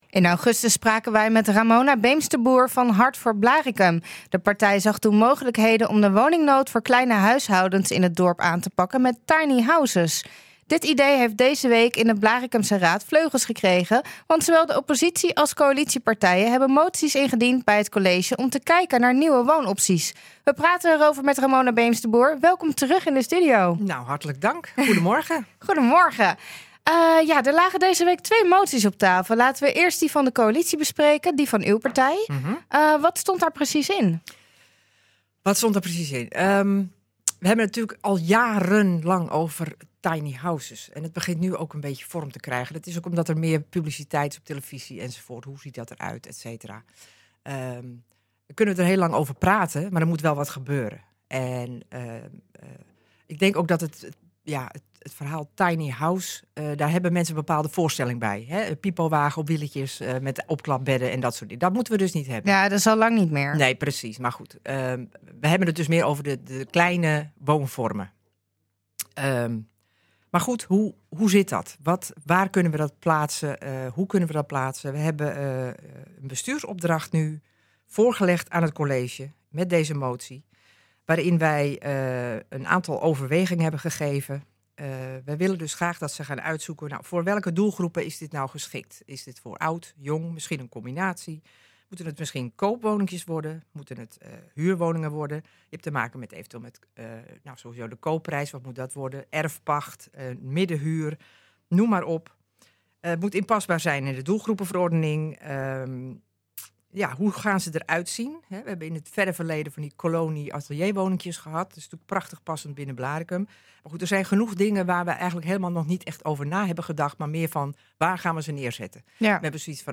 Raadslid Ramona Beemsterboer van coalitiepartij Hart voor Blaricum legt in het programma NH Gooi Zaterdag uit wat de moties moeten bereiken.